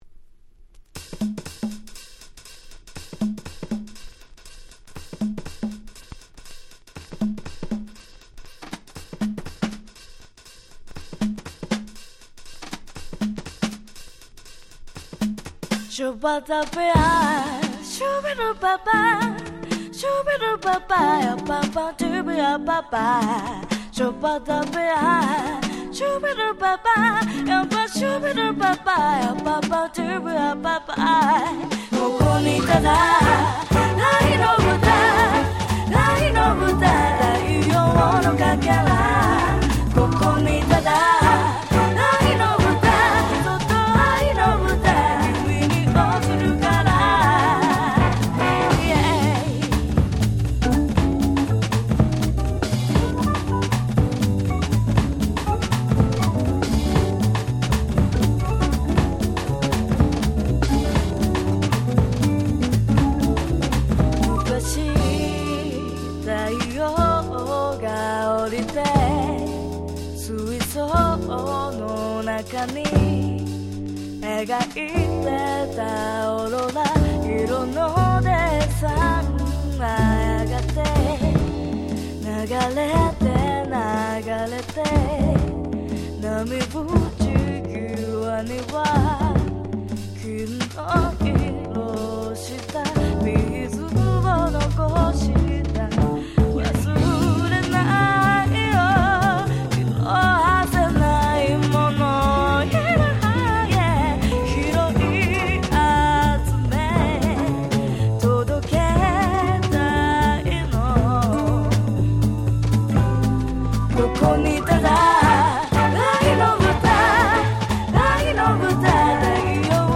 01' Super Nice Japanese R&B/Bossa !!
柔らかで温かいBossaな雰囲気が堪りません！